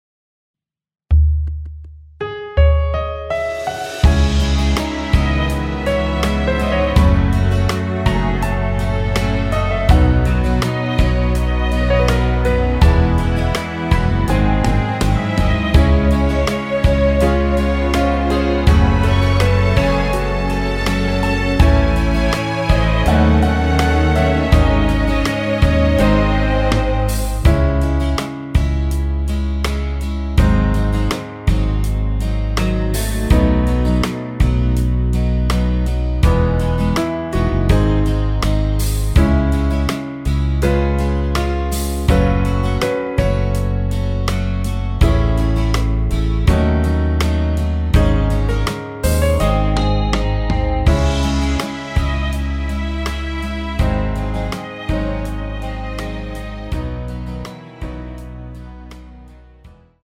원키에서(-1)내린 MR입니다.
C#m
앞부분30초, 뒷부분30초씩 편집해서 올려 드리고 있습니다.